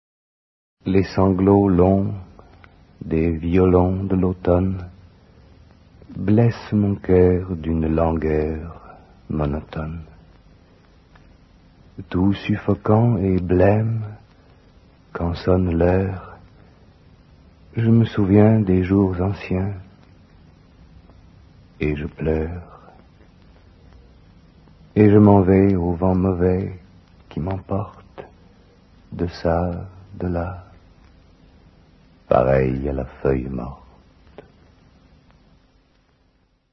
/wp-content/uploads/2011/01/chansondautomne.mp3 dit par Jean-Claude PASCAL Paul VERLAINE (Poèmes saturniens, 1866)